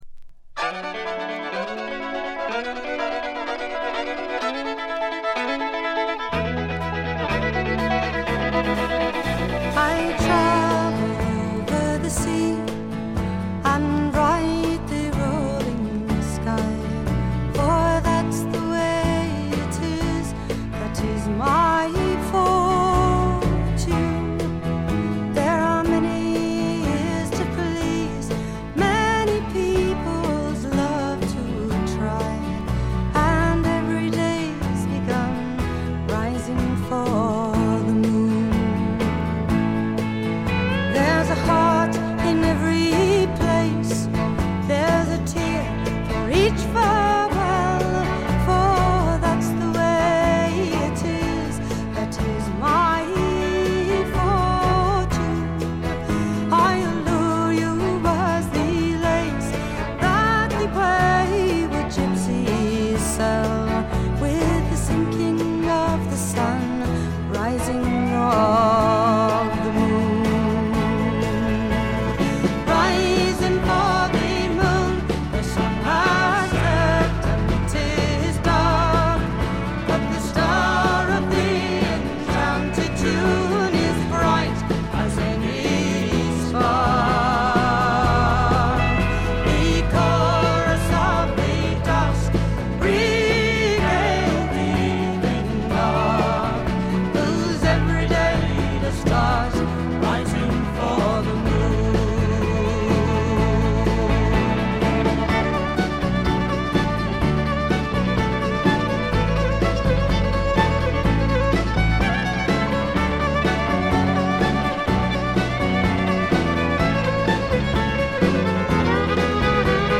チリプチ多めですが、大きなノイズはありません。
試聴曲は現品からの取り込み音源です。
Recorded and mixed at Olympic Sound Studios, London